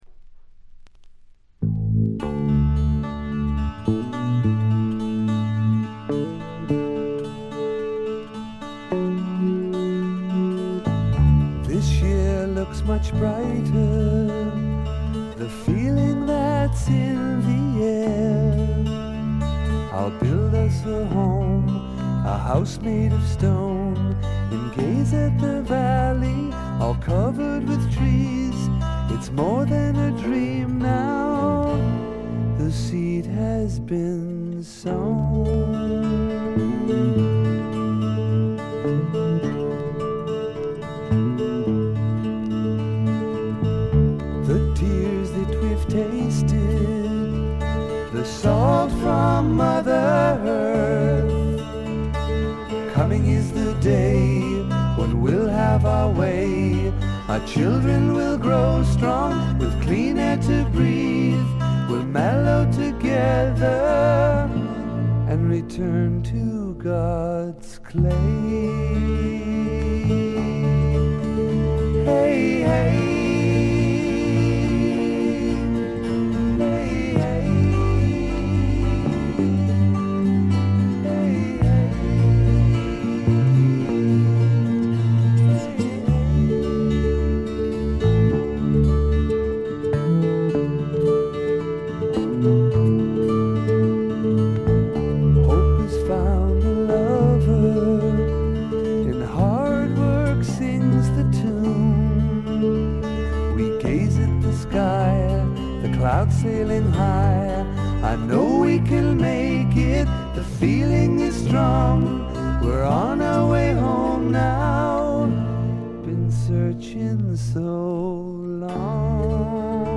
これ以外はわずかなノイズ感のみで良好に鑑賞できると思います。
試聴曲は現品からの取り込み音源です。